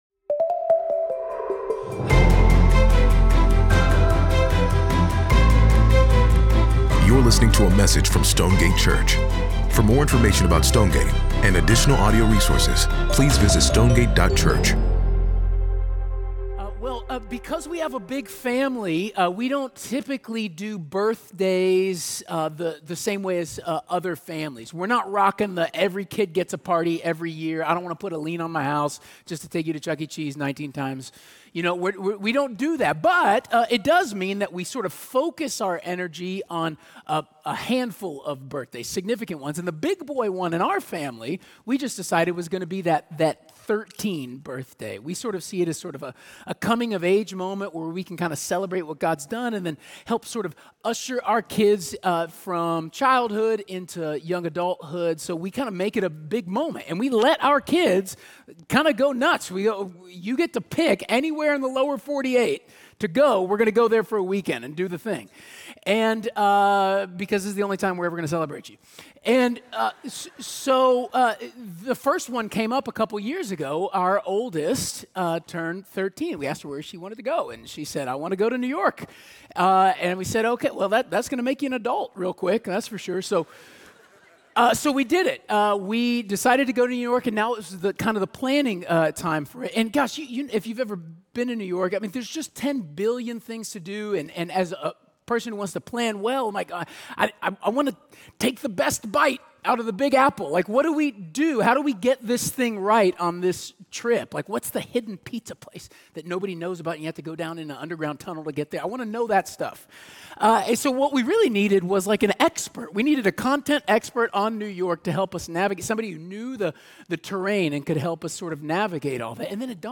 8.17 Sermon.mp3